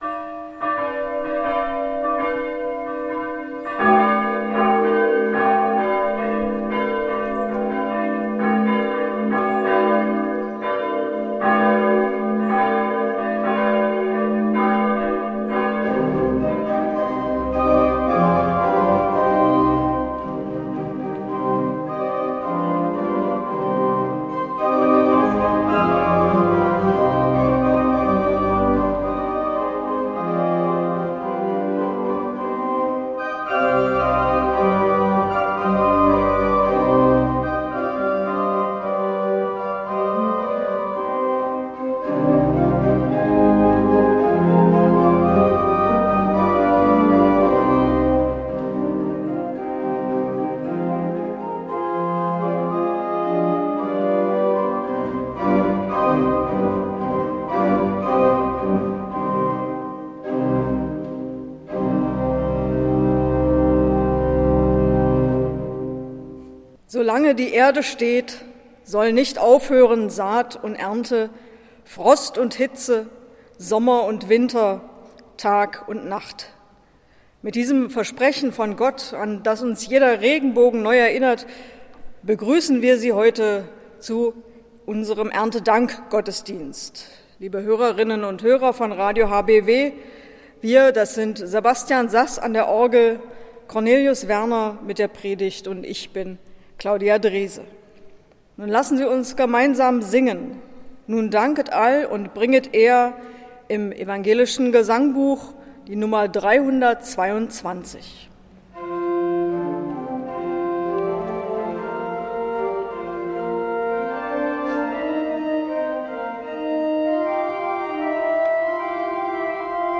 Radiogottesdienst
Der Evangelische Kirchenkreis Bernburg hat einen Radiogottesdienst für Sie aufgezeichnet, diesmal zum Erntedank.